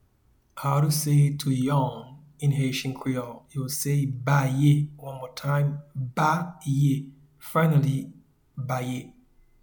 Pronunciation and Transcript:
Yawn-in-Haitian-Creole-Baye.mp3